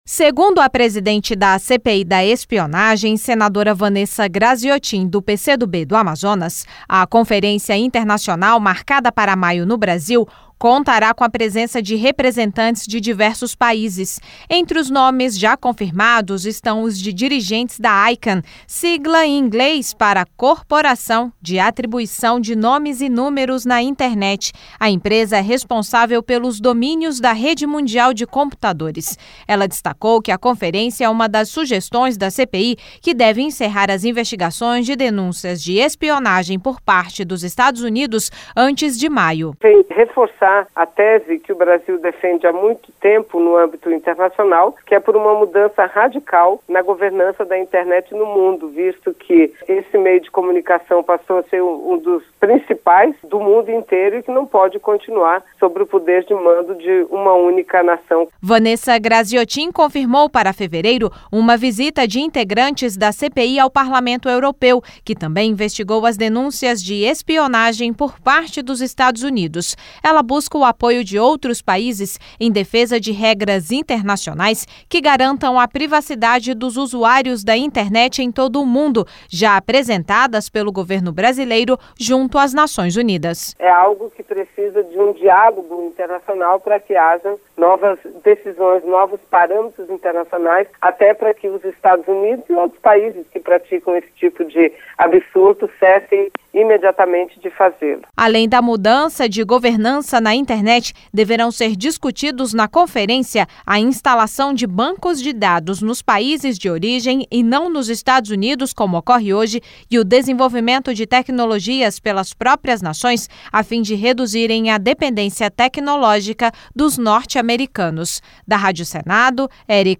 (Repórter)